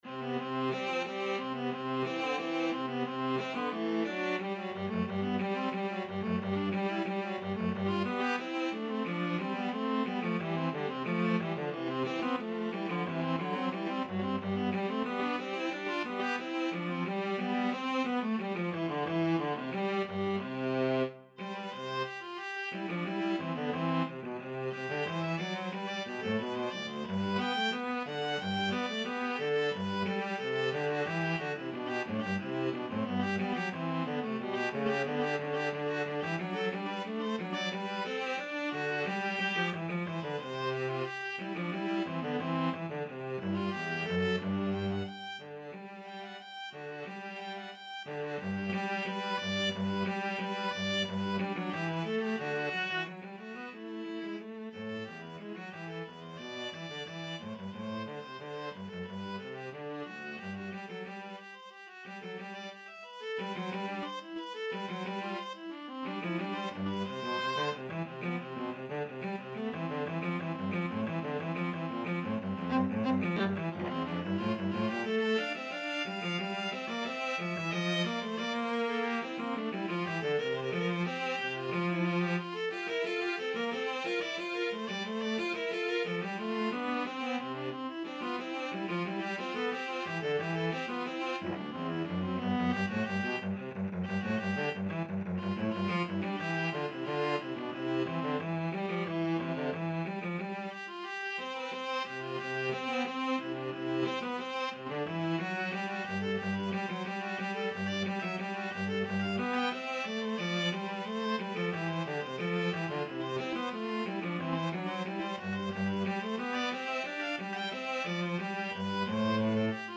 2/2 (View more 2/2 Music)
[Allegro moderato = c.90] (View more music marked Allegro)
Classical (View more Classical Viola-Cello Duet Music)